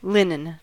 Ääntäminen
US : IPA : [ˈlɪn.ən]